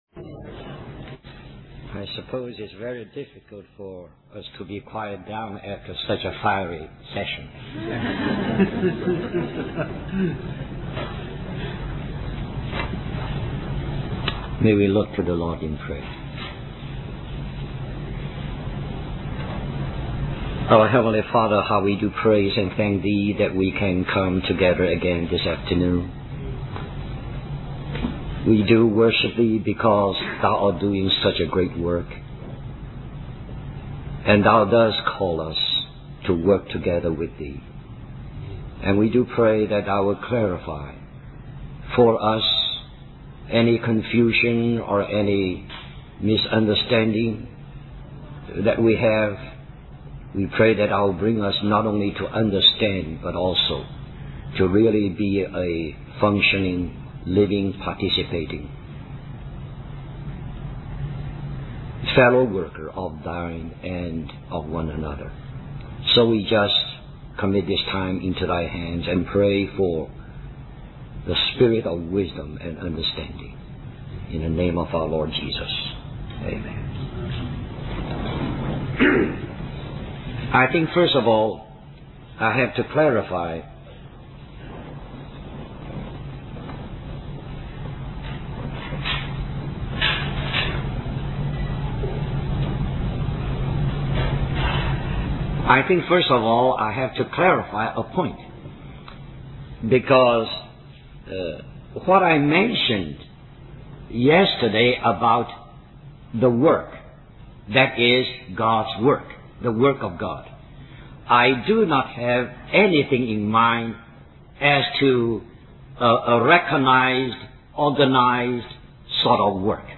Christian Family Conference We apologize for the poor quality audio
The second side of the master tape was inaudible
Question and Answer